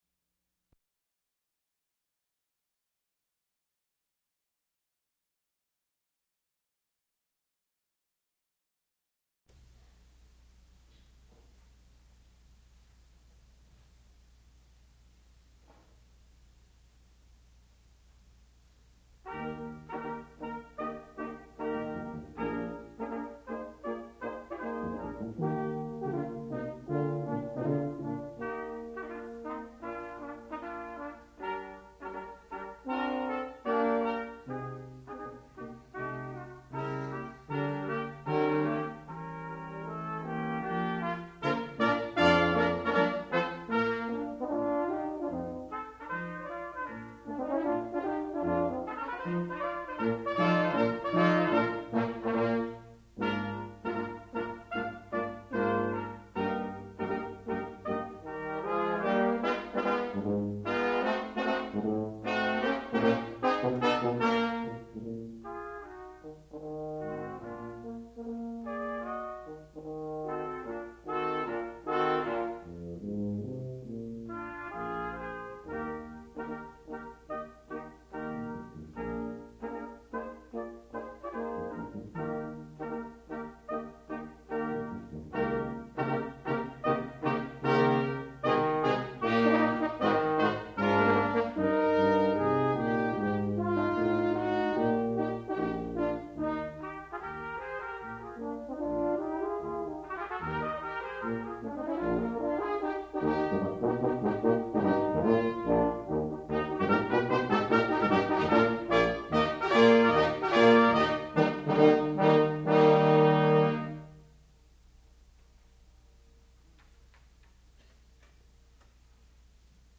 sound recording-musical
classical music
trumpet
horn